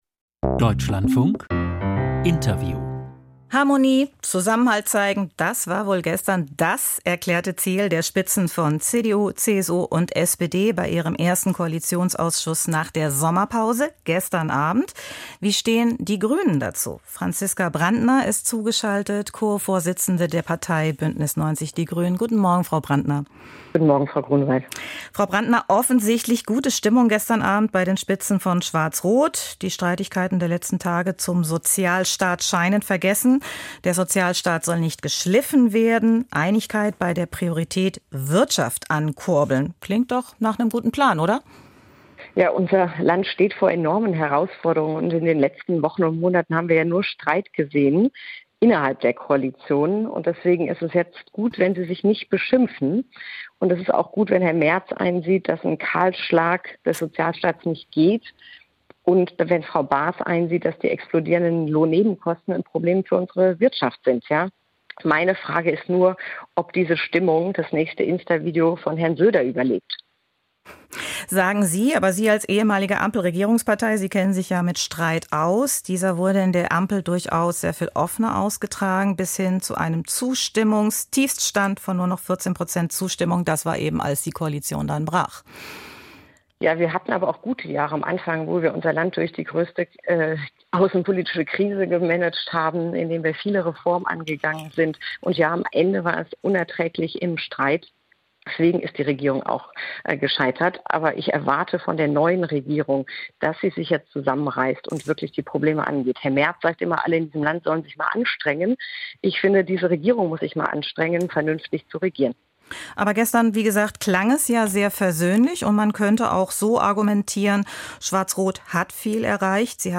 Rückblick Koalitionsausschuss: Interview Franziska Brantner, Grüne, Co-Chefin